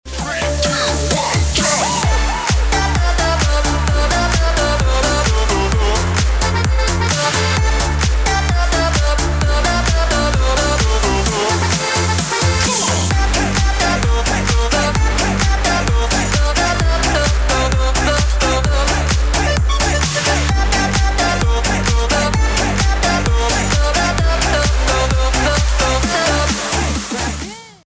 • Качество: 320, Stereo
мужской голос
веселые
Electronic
басы
Стиль: melbourne bounce